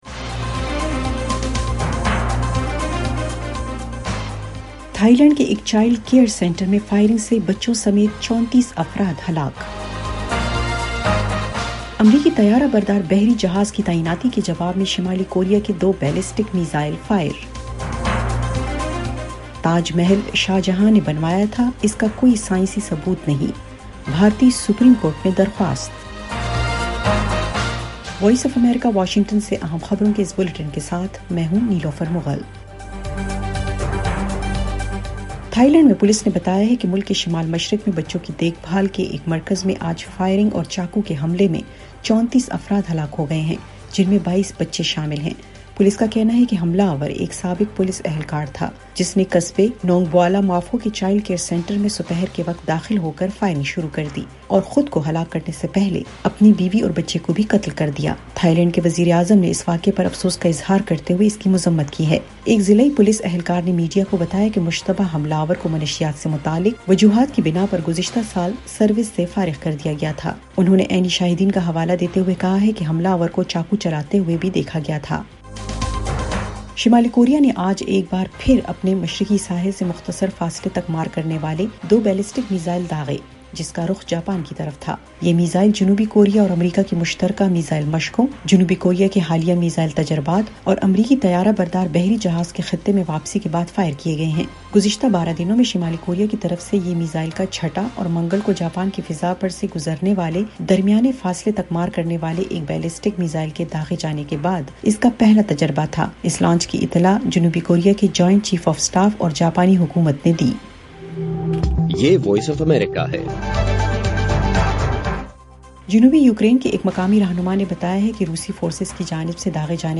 ایف ایم ریڈیو نیوز بلیٹن : شام 6 بجے